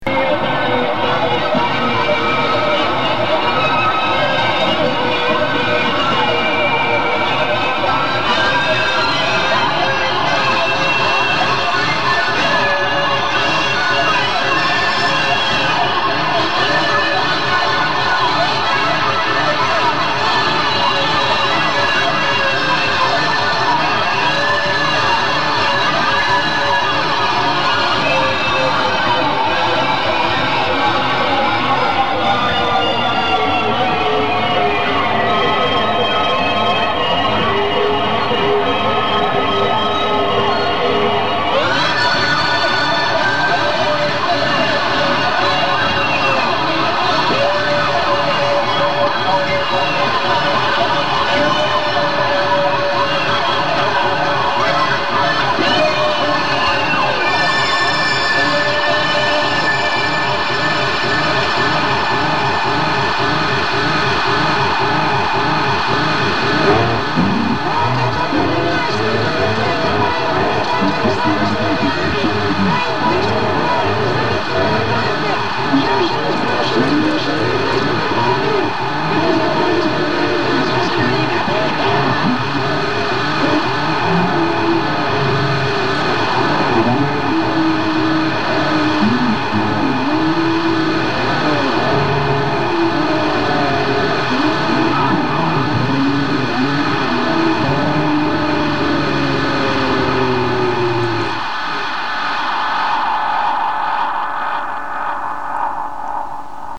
R.: ecco qua il nostro album sperimentale.